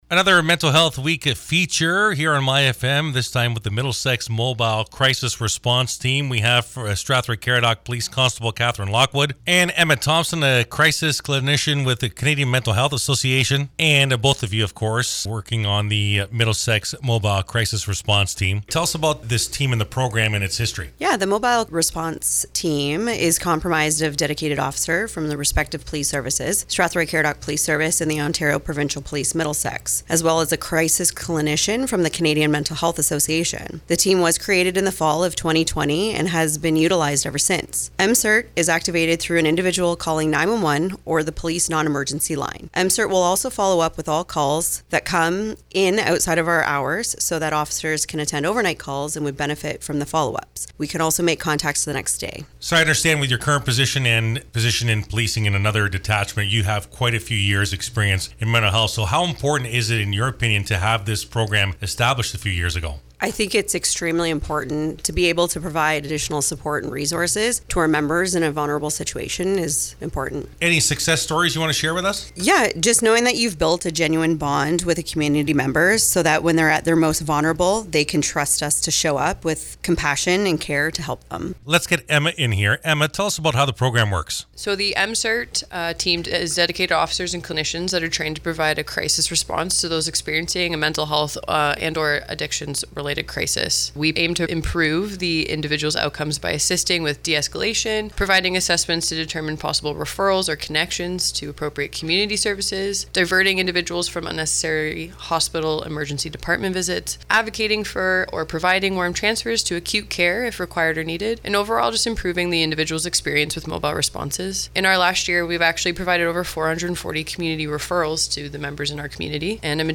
Here is the complete interview.